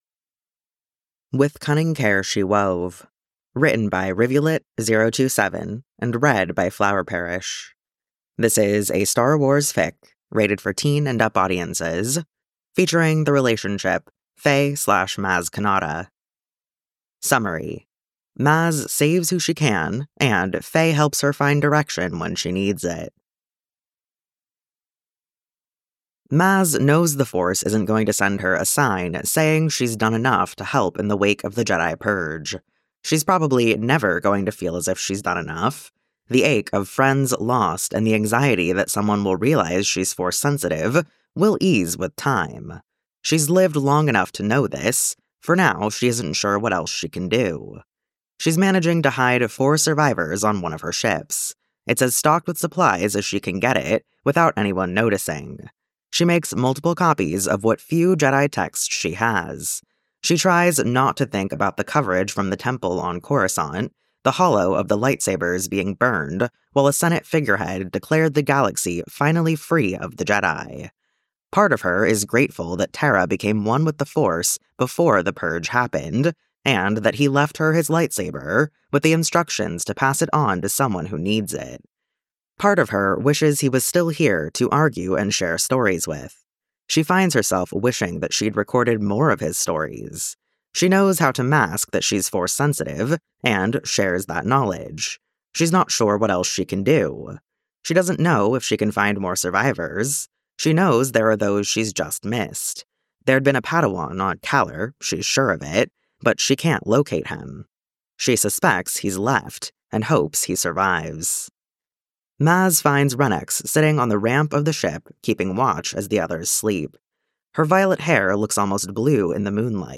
comment to the podficcer here